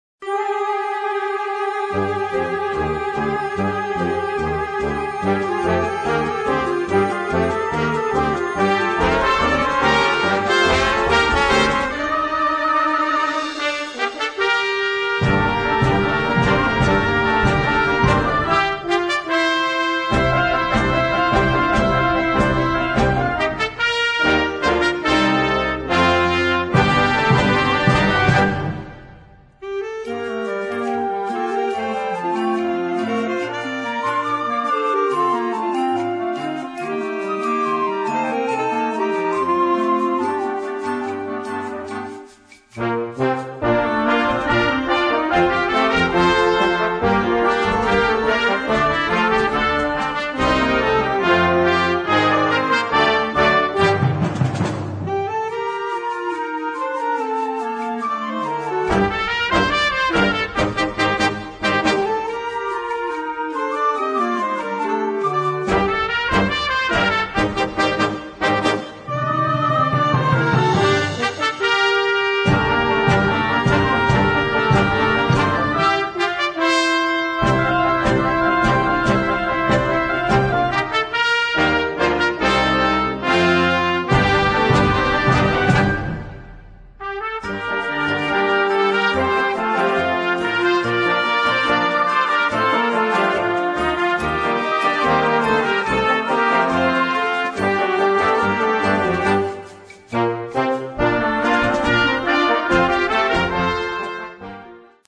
driving rhythms, memorable melodies, interesting harmonies